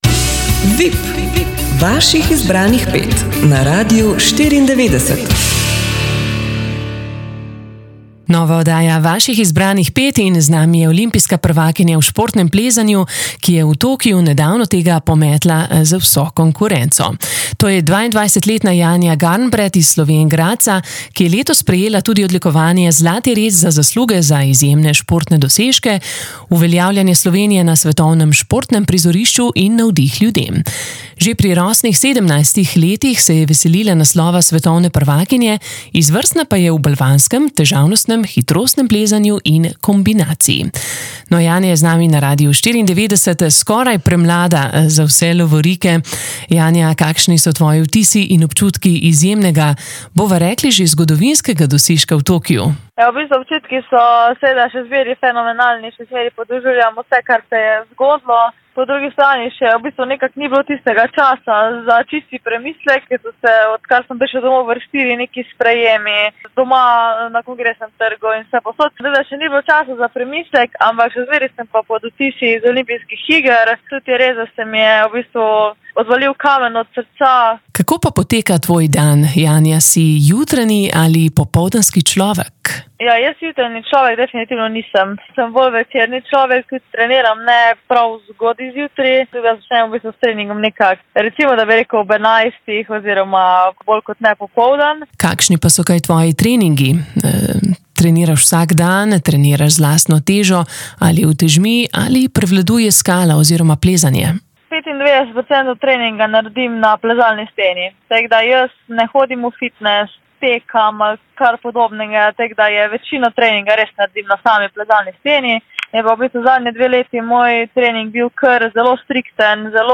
Današnja oddaja Vaših izbranih pet bo zmagoslavno obarvana, v naši družbi bo simpatična olimpijska prvakinja v športnem plezanju, ki je v Tokiu pred 14 dnevi pometla z vso konkurenco.